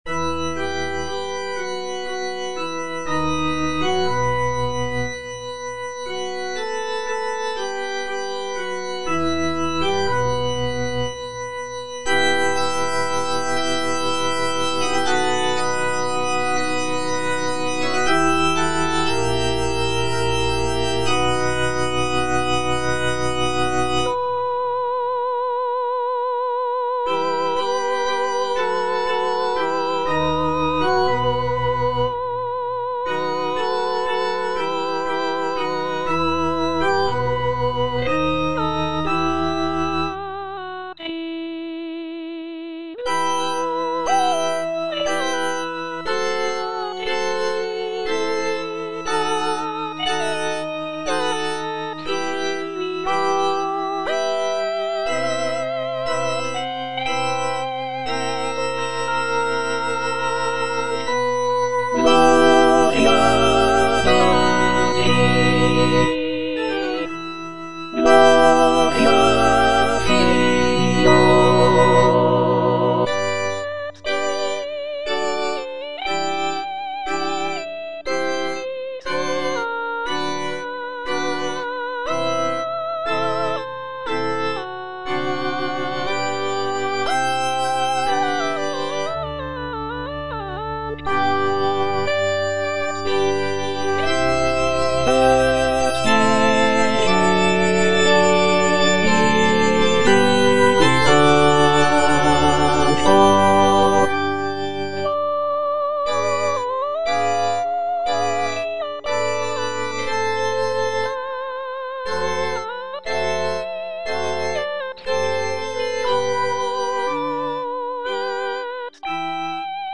All voices
sacred choral work